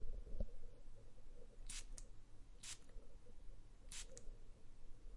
Perfume Spray
描述：Single burst of mens cologne spray can be multiplied and used in any way.
标签： squirt mensperfume spray cologne perfume burst fieldrecording OWI
声道立体声